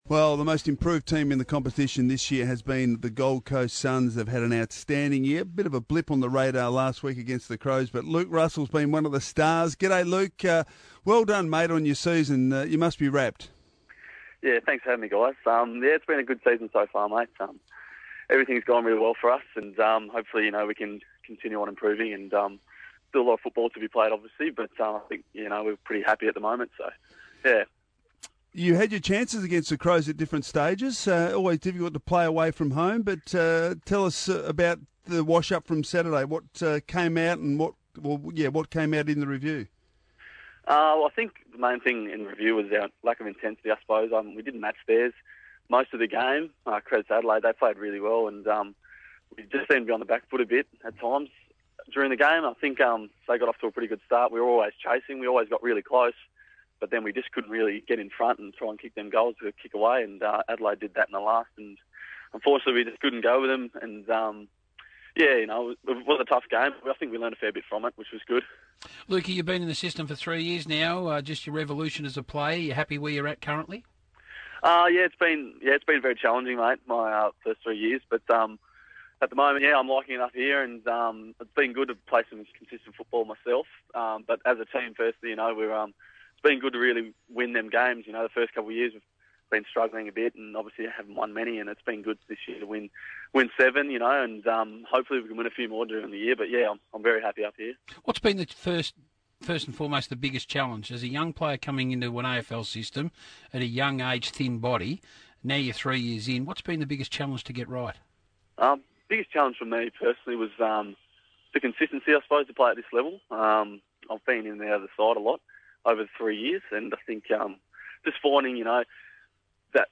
spoke with RSN Sports Radio.